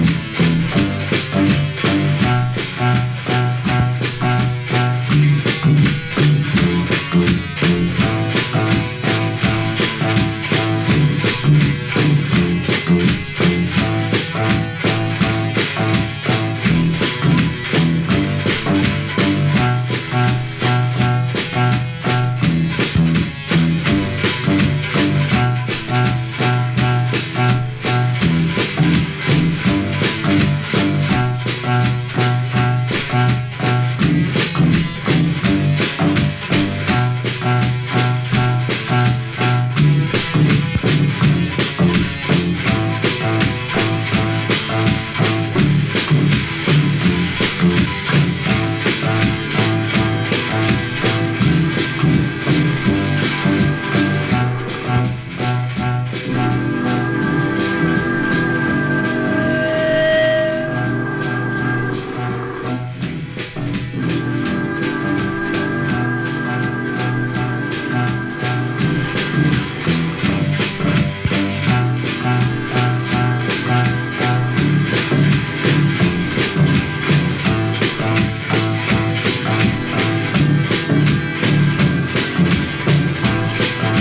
Música bélica.